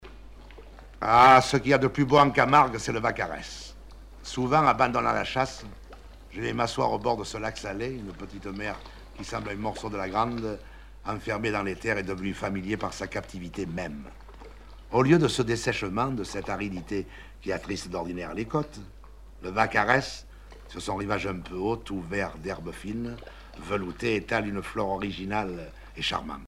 Genre récit
Catégorie Récit